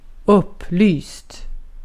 Ääntäminen
IPA : /ˈlɪt/